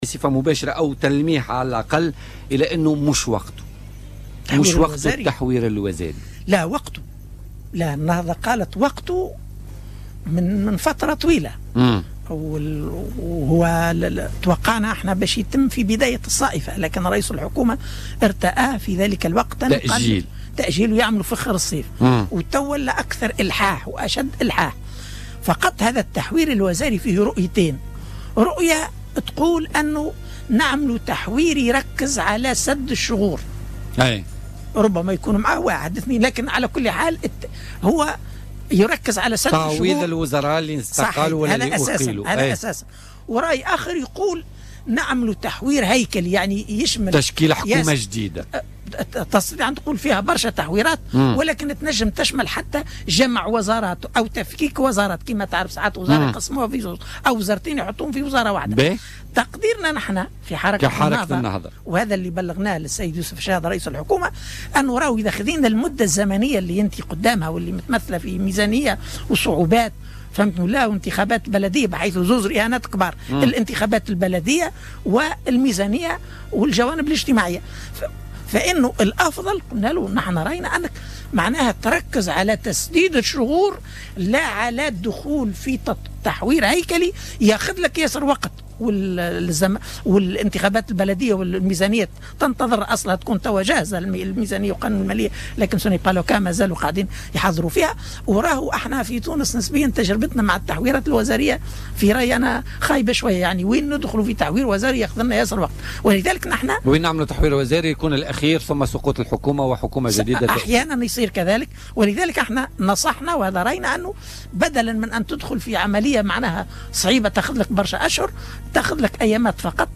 وكشف ضيف "بوليتيكا" النائب علي العريض ان النهضة تميل إلى مقاربة سدّ الشغور بدلا من تحوير وزاري بالنظر للعوامل التي ذكرها وضيق الوقت مشيرا إلى أنه مقترح الحركة بمناسبة المشاورات التي أطلقها رئيس الحكومة يوسف الشاهد.